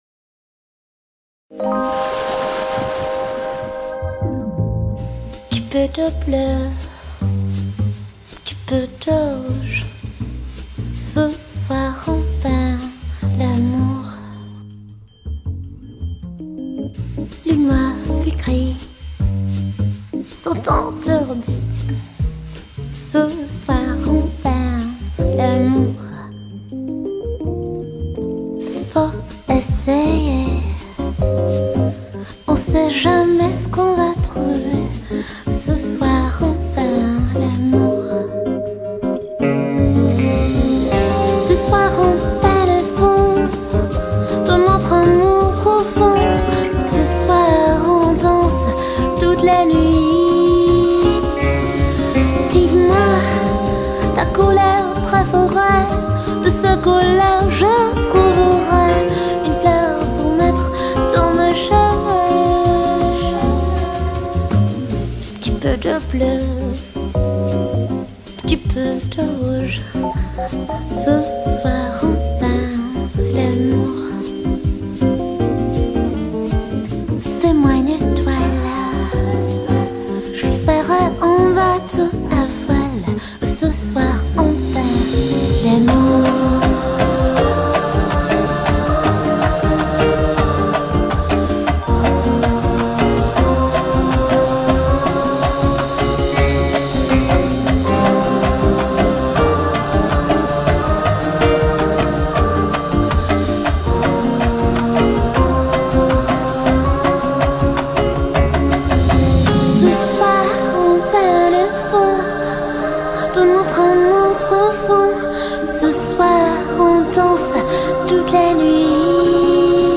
■Ladies' Jazz■